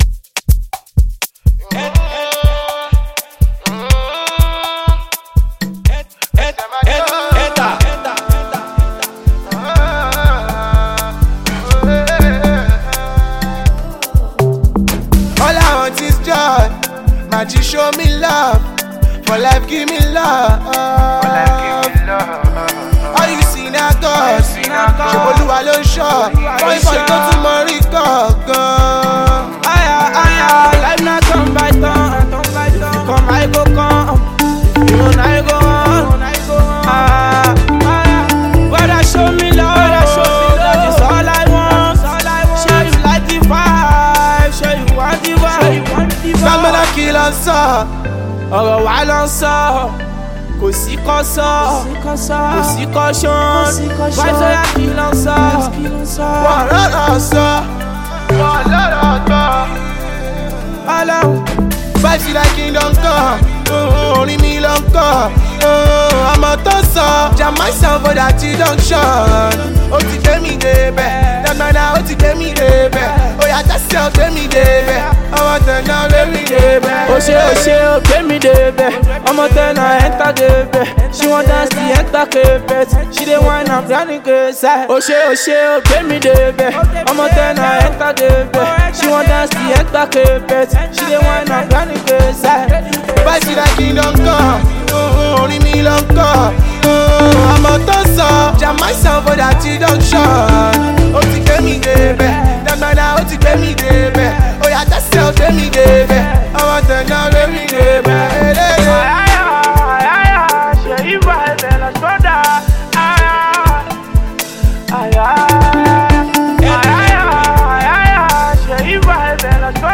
Nigerian fast rising street-hop singer